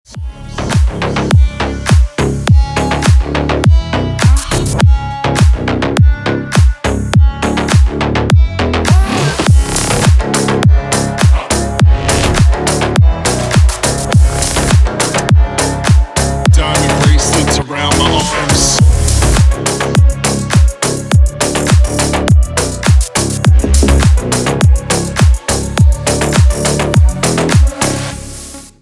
Клубная нарезка